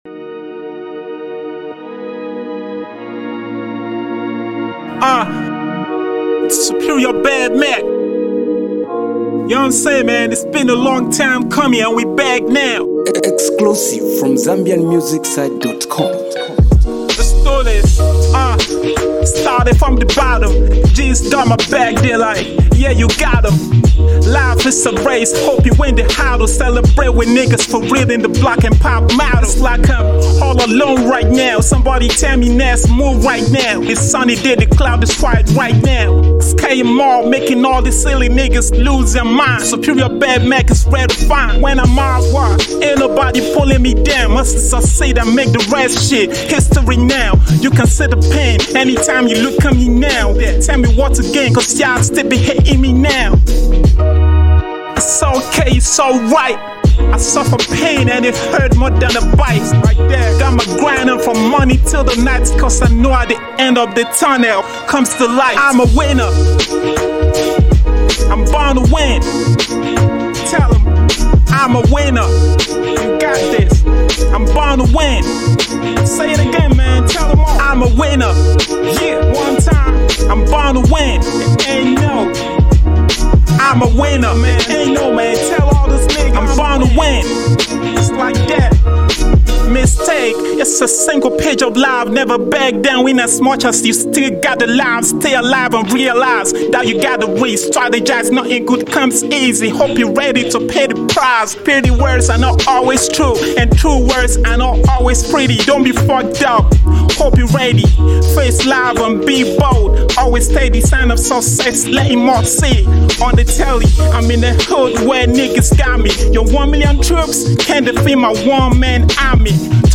Super Incredible Rapper